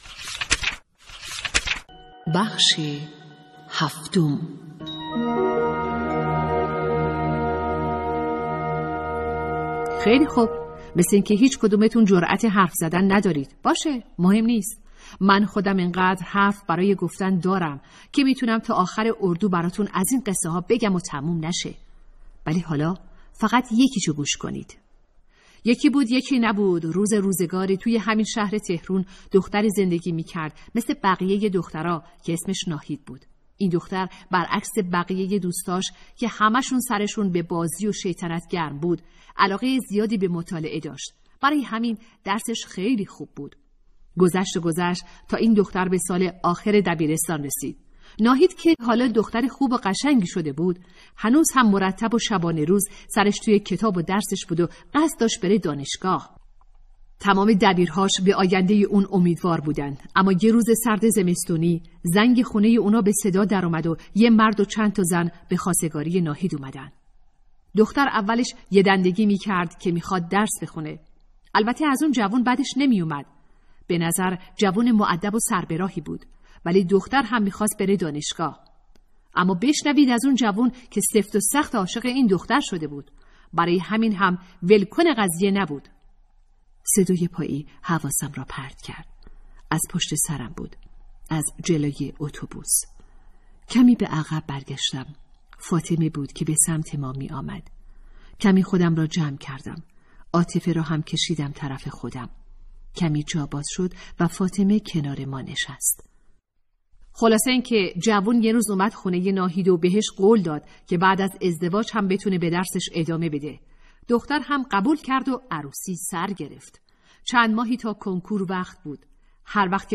کتاب صوتی | دختران آفتاب (07)